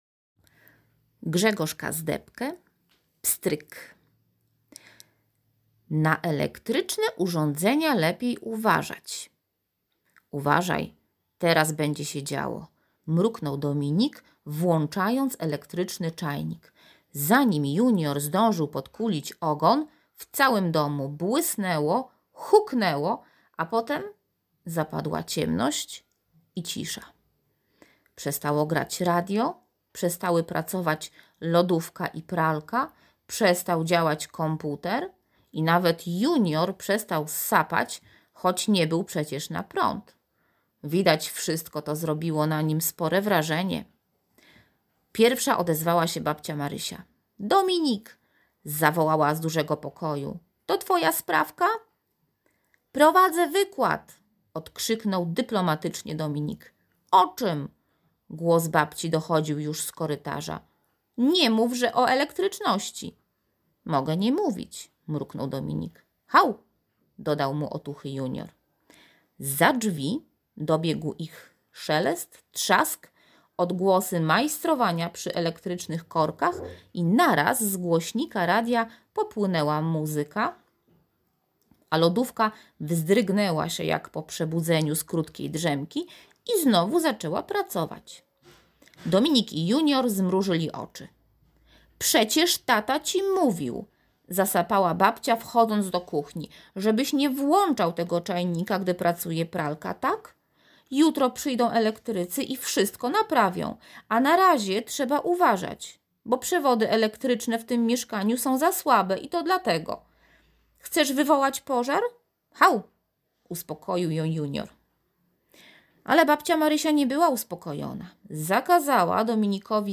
2.Posłuchajcie teraz opowiadania,które Wam przeczytam i postarajcie się zapamiętać,jak nazywali się jego bohaterowie.
opowiadanie.mp3